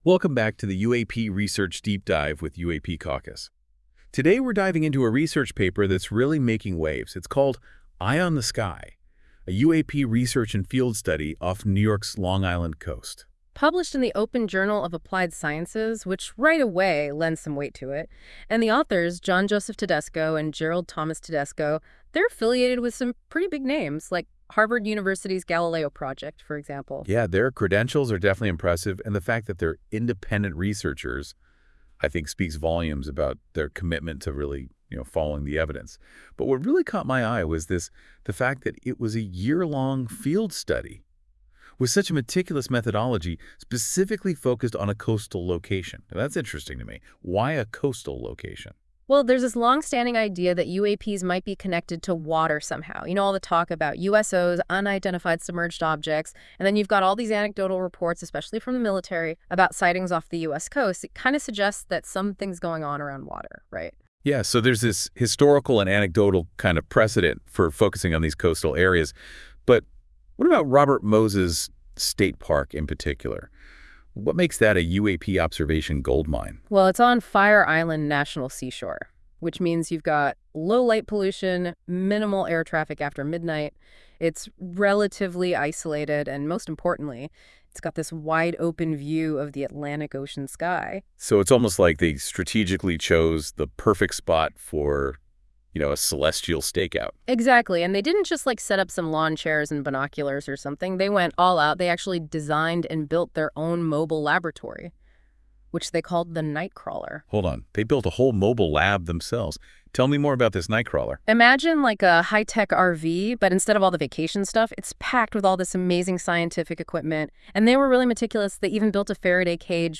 This AI-generated audio may not fully capture the research's complexity.